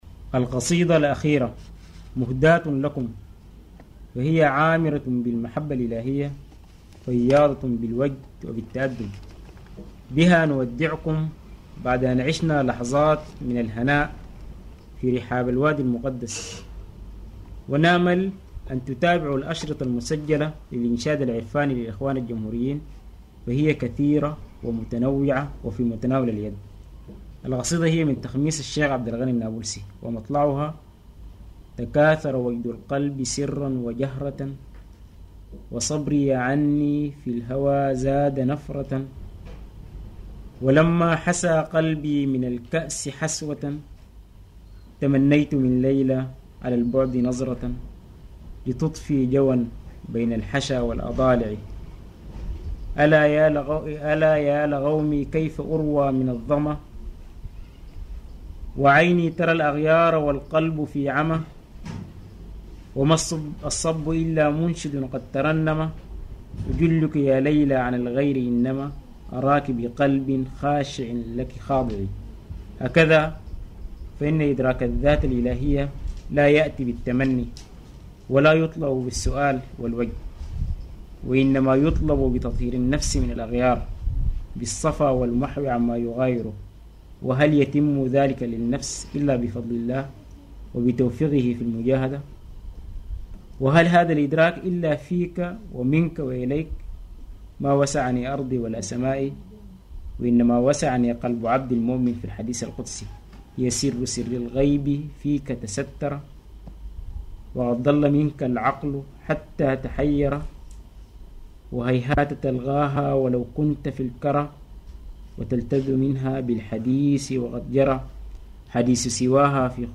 إنشاد